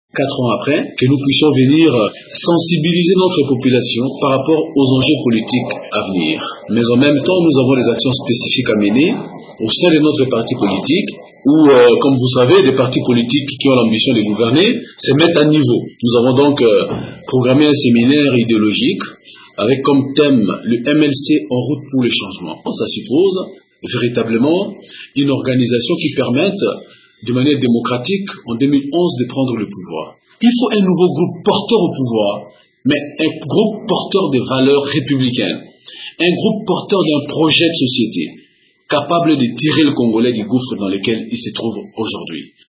Au cours d’un point de presse, ce dimanche 19 décembre à Mbandaka,  le secrétaire général adjoint du Mouvement de libération du Congo (MLC), Jean-Lucien Bussa, a réaffirmé que son participera aux prochaines élections et aura des candidats à tous les niveaux en vue d’assurer le changement en RDC.